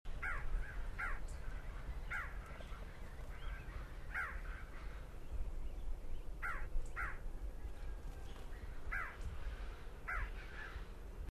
contact call ssp paraguayensis recorded PN Tte Enciso, Departamento Boqueròn
Thamnophiluscaerulescensparaguayensisoct08.mp3